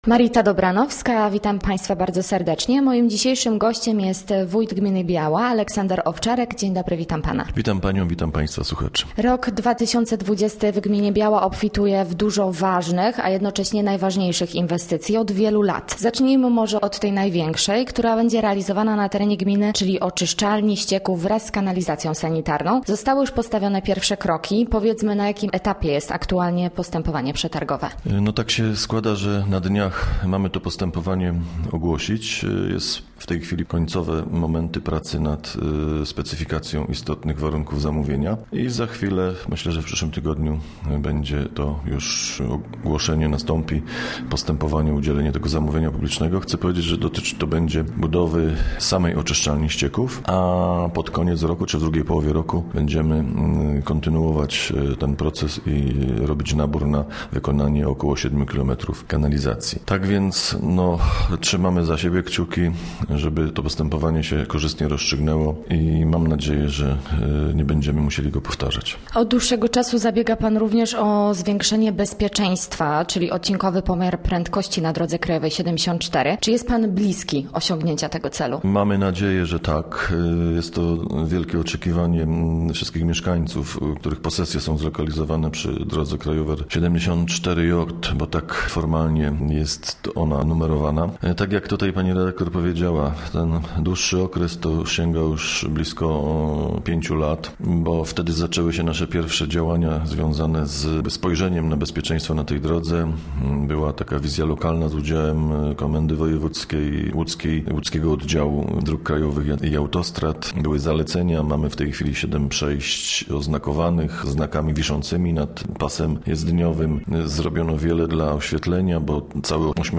Gościem Radia ZW był wójt gminy Biała, Aleksander Owczarek